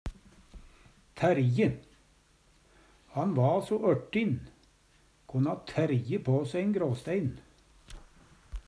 tærje - Numedalsmål (en-US)
DIALEKTORD PÅ NORMERT NORSK tærje terge, erte Infinitiv Presens Preteritum Perfektum Eksempel på bruk Han va so ørtin, konna tærje på se ein gråstein.